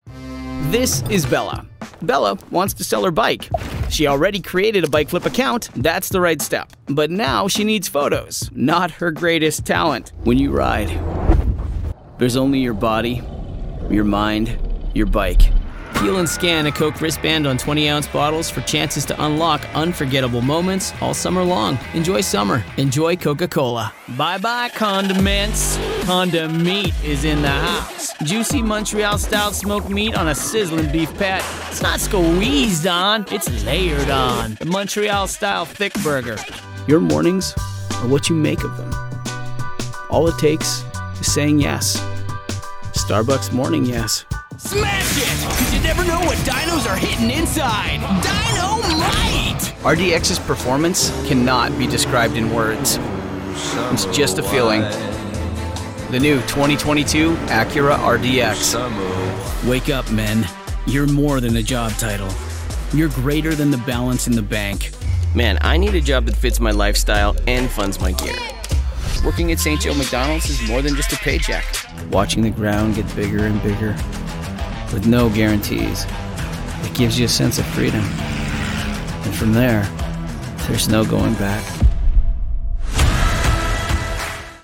English (Canadian)
Conversational
Real
Believable